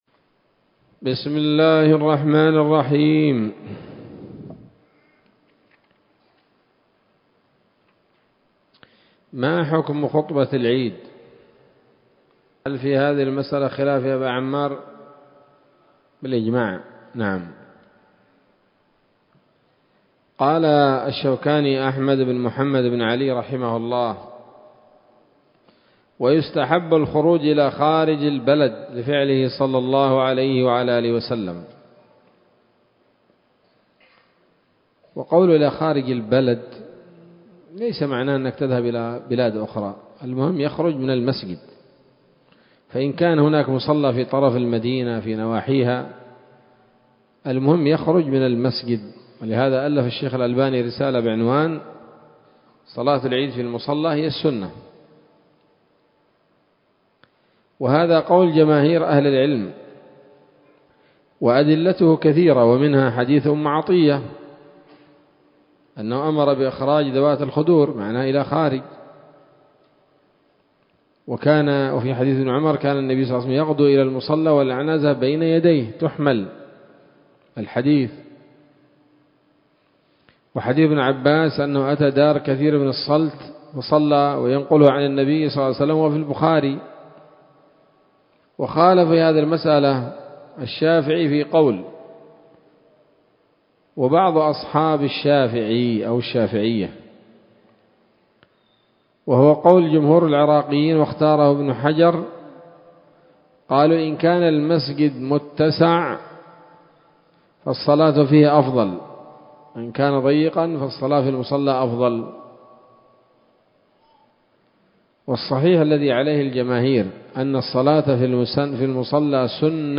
الدرس الثامن والأربعون من كتاب الصلاة من السموط الذهبية الحاوية للدرر البهية